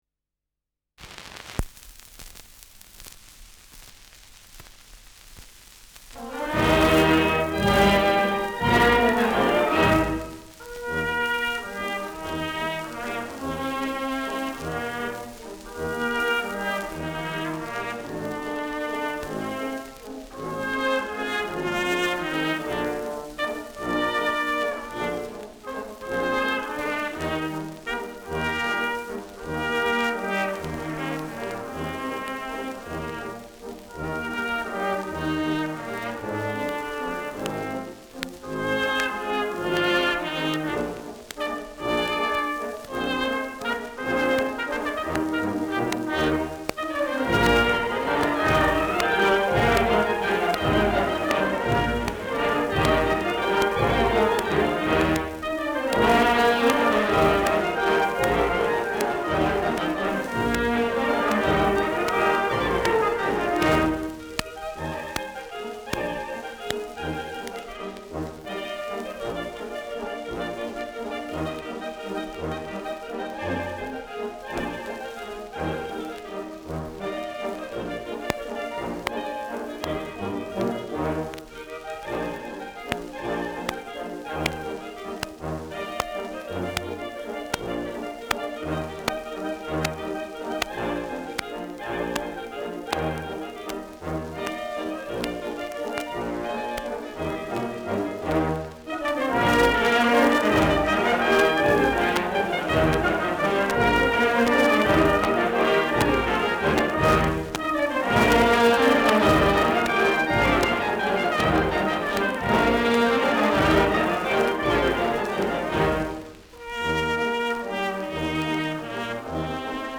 Schellackplatte
Handschriftlich: „schnell“
[München] (Aufnahmeort)